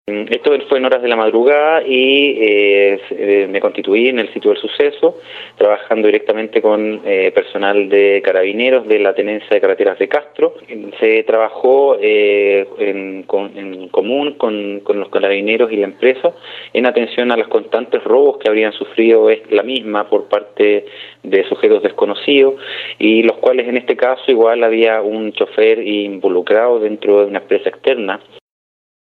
El persecutor señaló, en la formalización de investigación realizada en el Juzgado de Garantía de Castro, que los hechos ocurrieron alrededor de las 2 de la madrugada, cuando cuatro sujetos concertados con el chofer de un camión de la empresa de salmones AQUA CHILE, se encontraban en la Ruta 5 Sur a la altura del Km. 1163 de Dalcahue, procediendo a sustraer 240 salmones avaluados en la suma de 5 millones 500 mil pesos.
04-FISCAL-CASTRO-2.mp3